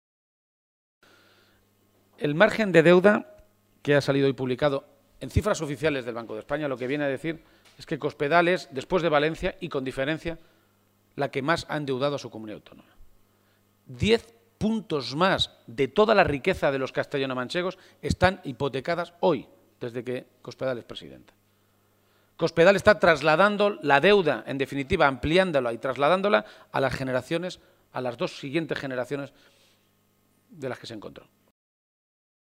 Emiliano García-Page durante la rueda de prensa celebrada en Guadalajara